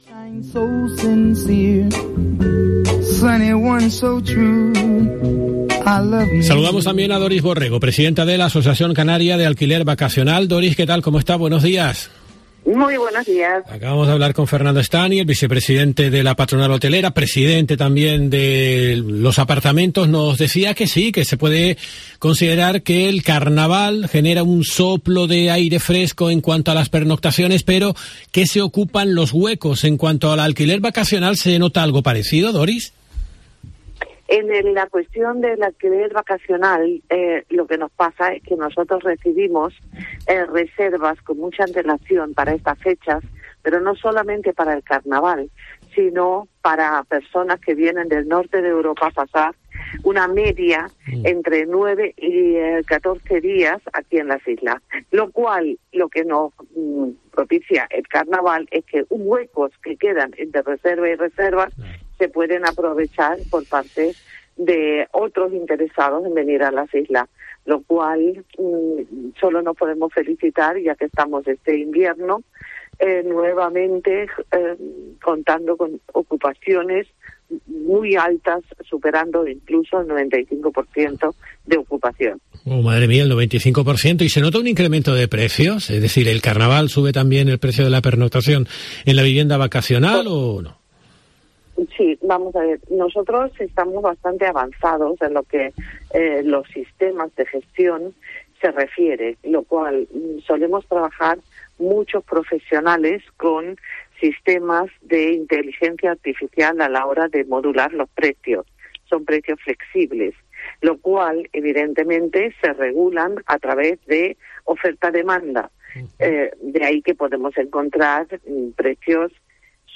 En nuestros micrófonos cuenta que el turista que busca una vivienda vacacional lo hace con el fin de sentirse “como en casa”.